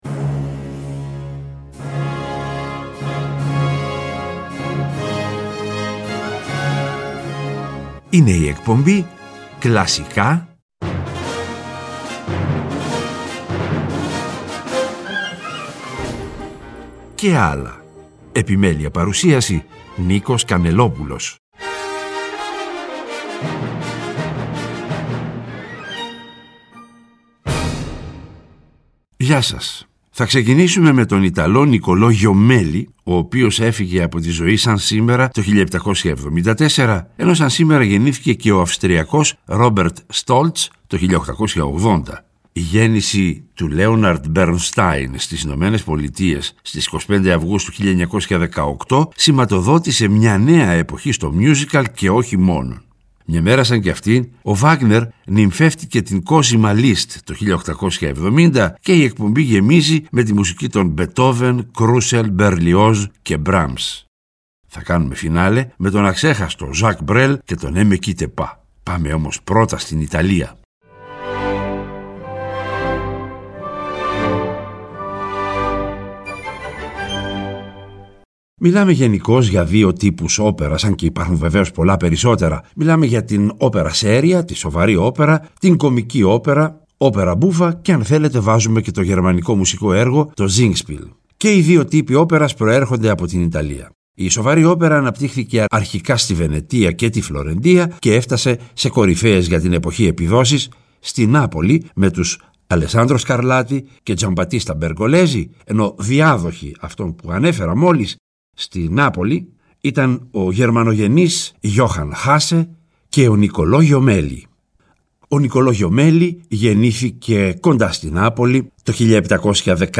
Και, προς το τέλος κάθε εκπομπής, θα ακούγονται τα… «άλλα» μουσικά είδη, όπως μιούζικαλ, μουσική του κινηματογράφου -κατά προτίμηση σε συμφωνική μορφή- διασκ